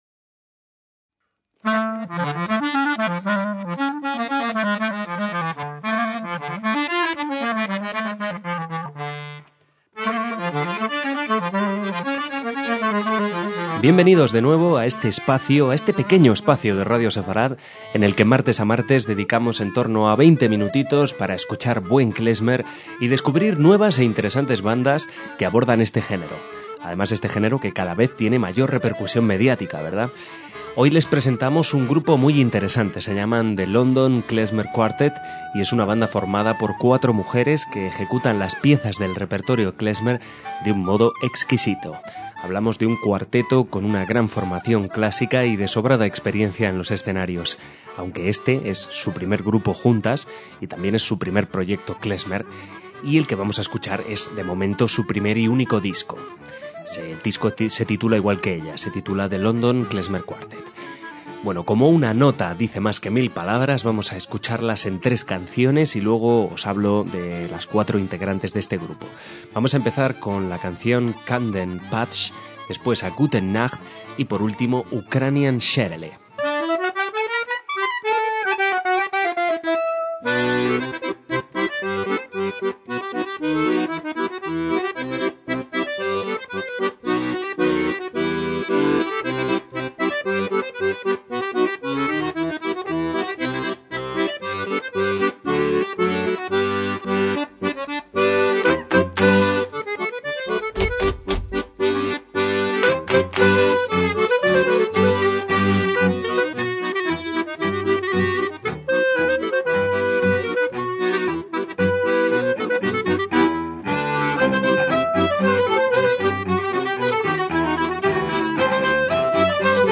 MÚSICA KLEZMER - El London Klezmer Quartet es un grupo de músicas formado en 2009 y que desde entonces ha recorrido el mundo y editado cuatro CDs.